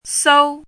chinese-voice - 汉字语音库
sou1.mp3